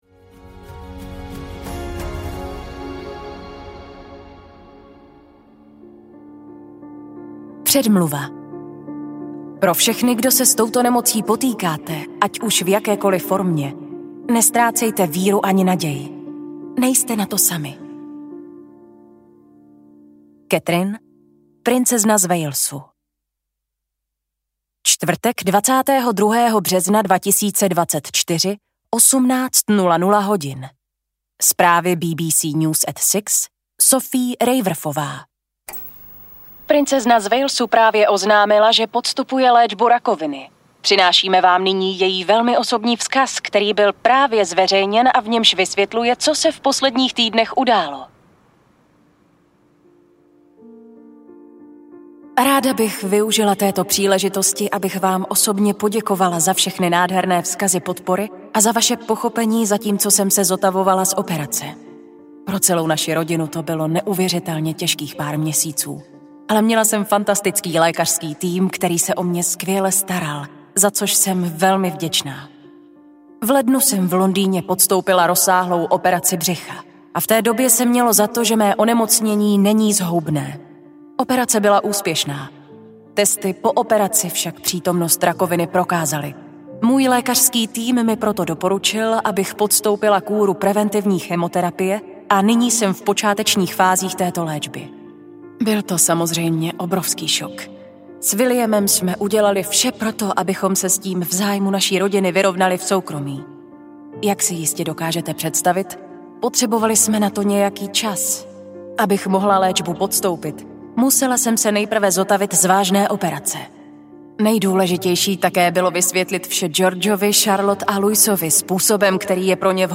Ukázka z knihy
catherine-princezna-z-walesu-audiokniha